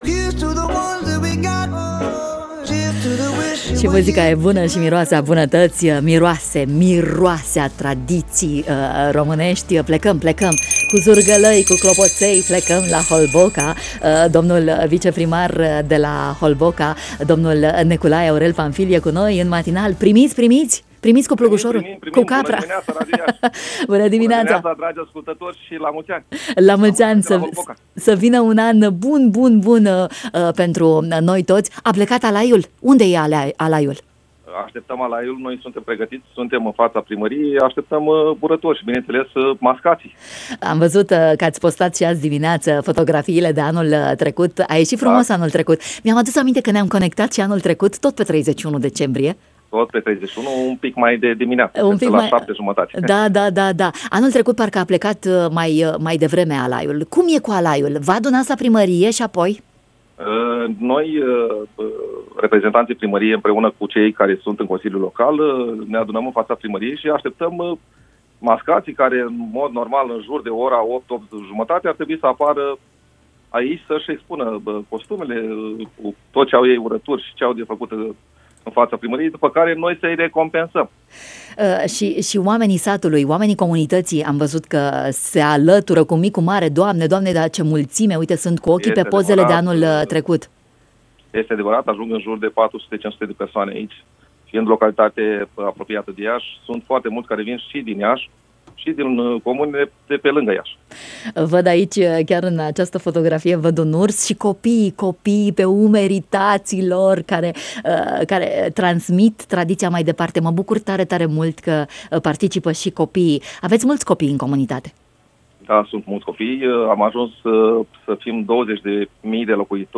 Viceprimarul Neculai-Aurel Pamfil în matinal